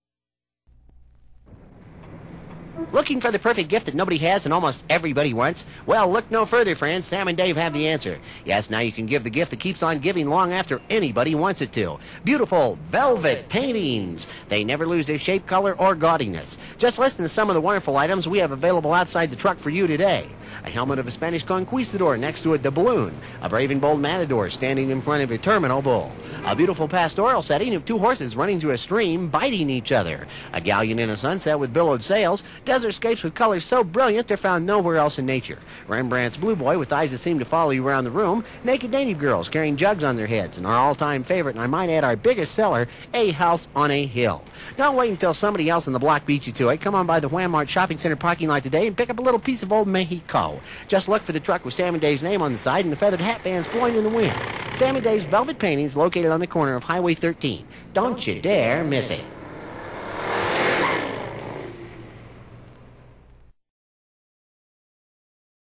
He has used the studio recording equipment and software at KONA to dub the LP tracks sans clicks and pops!.